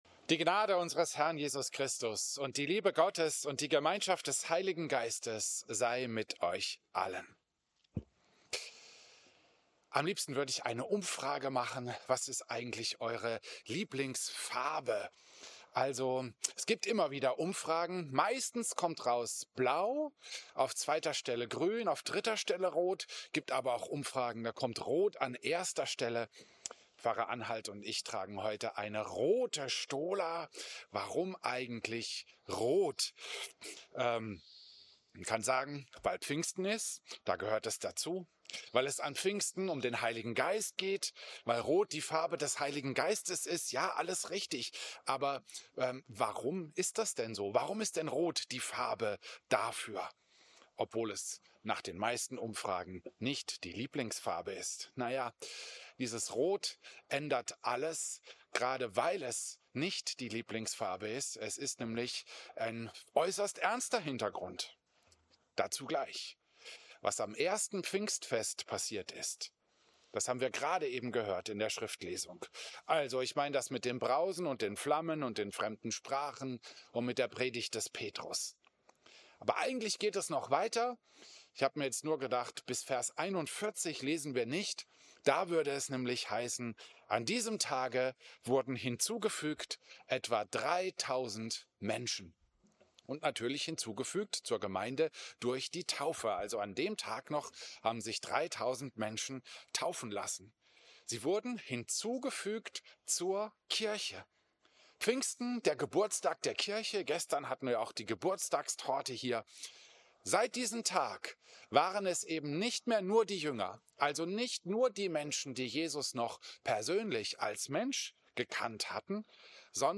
Predigt-Pfingstmontag-2025-Mono.mp3